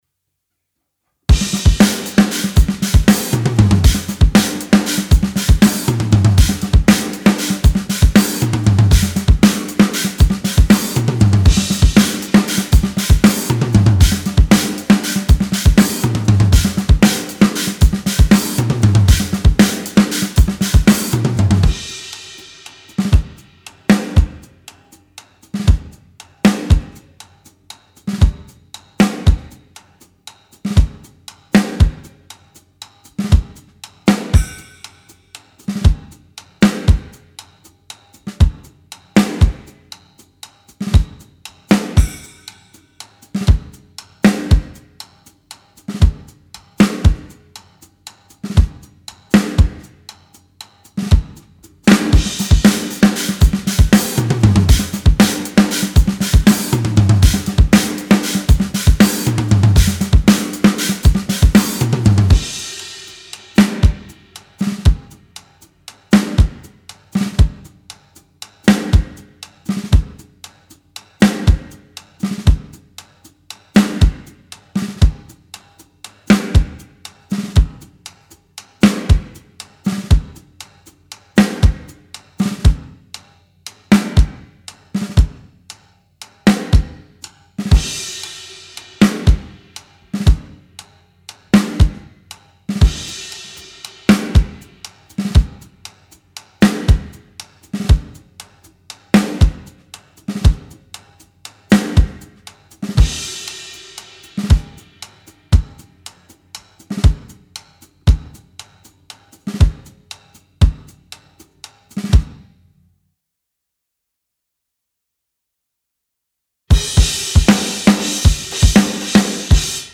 Mathrock
Genre:Mathrock, Prog
Tempo:118 BPM (5/4)
Kit:Rogers 1977 Big R 22"
Mics:14 channels